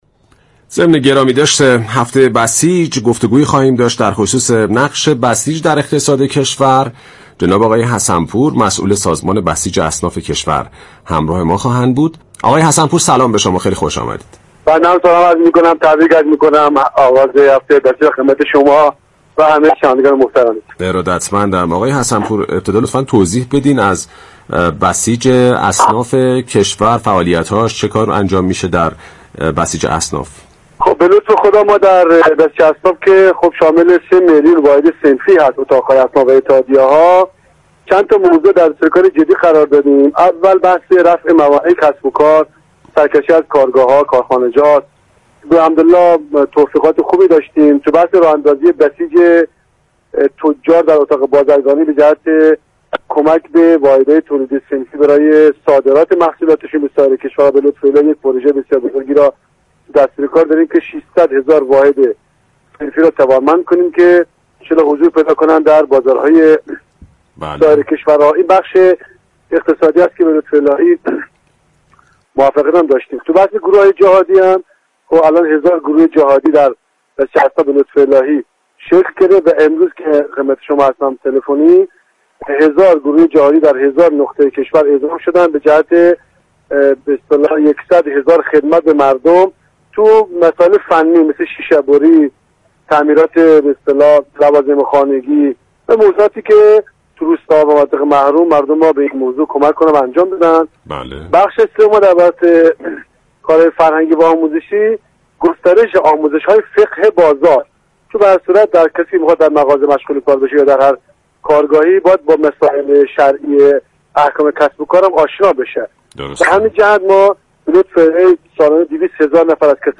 ‌به گزارش پایگاه اطلاع رسانی رادیو تهران، غلامرضا حسن پور رئیس سازمان بسیج اصناف كشور در گفت و گو با «بازار تهران» درخصوص اقدامات بسیج اصناف همزمان با هفته بسیج، اظهار داشت: بسیج اصناف شامل 3 میلیون واحد صنفی است.